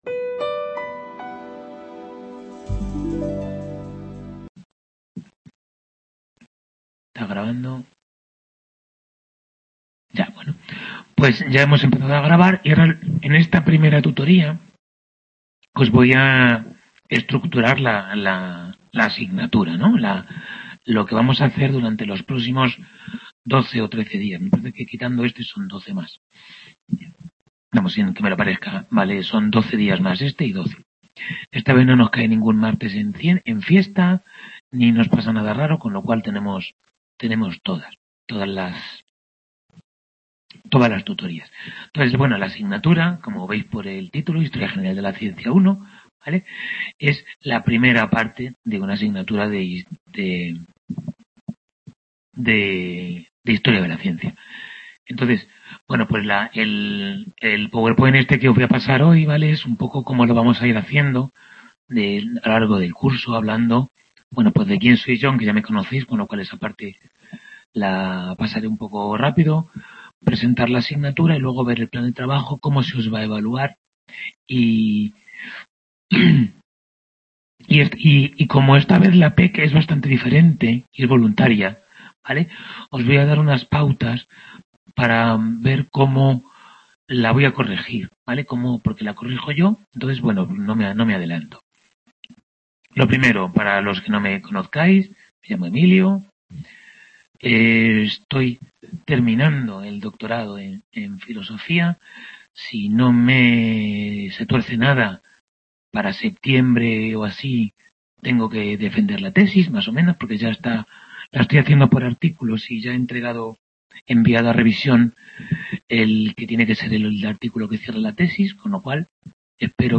Tutoría 0-a de Historia General de la Ciencia I | Repositorio Digital